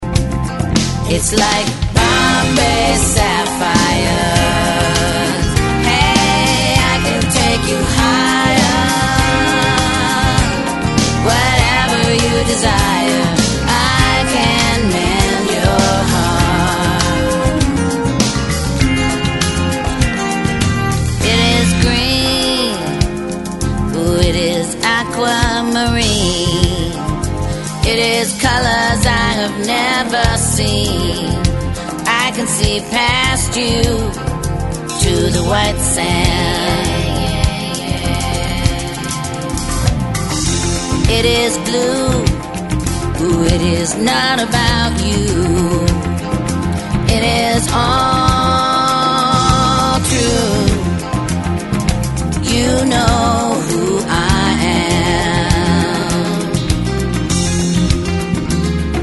Guitars, Bass
Keyboards
Drums
Percussion
Background Vocals